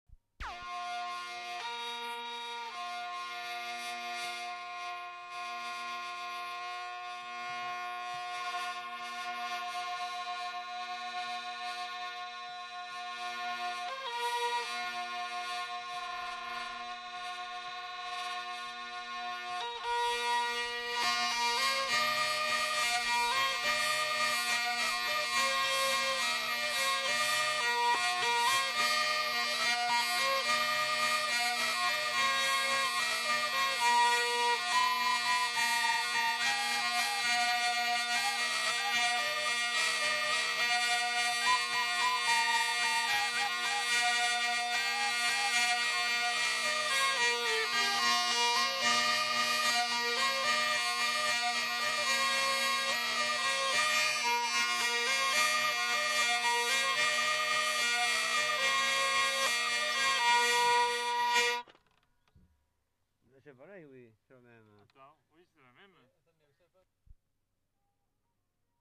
Aire culturelle : Gabardan
Genre : morceau instrumental
Instrument de musique : vielle à roue
Danse : valse